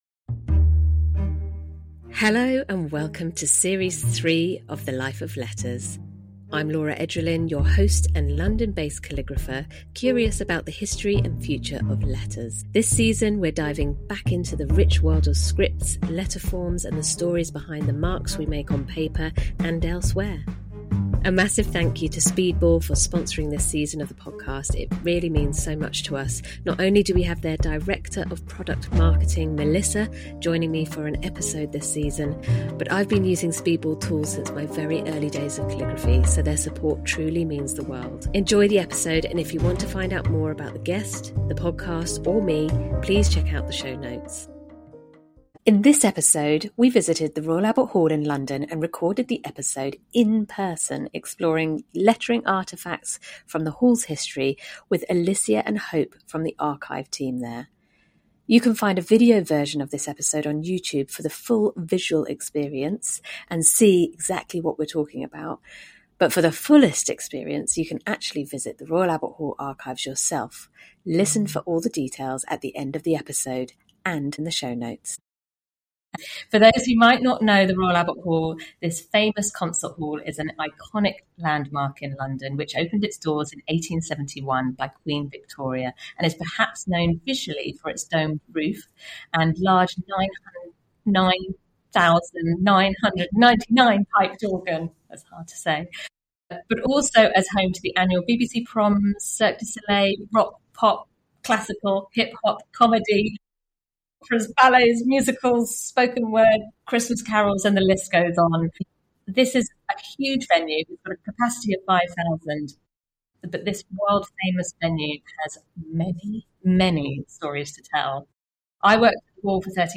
The conversation delves into personal correspondence, postcards, and the impact of typography on historical events. The episode also highlights the changing nature of ticketing and public engagement through tours of the archives, emphasizing the importance of storytelling in preserving history.